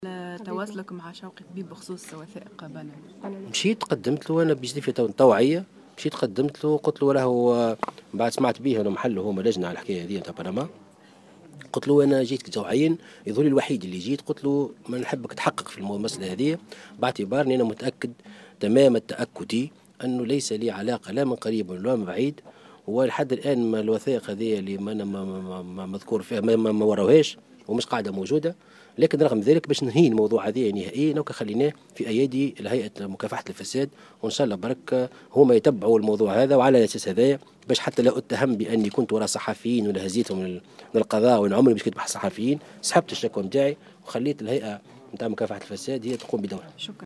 وأكد في تصريحات صحفية على هامش ندوة صحفية نظمها حول الإعداد للمؤتمر التأسيسي لحزبه، أكد انتفاء علاقتة بهذا الملف ، موضحا أنه لم يتم إلى حد الآن الكشف عن أي وثيقة تثبت تورطه في هذا الملف.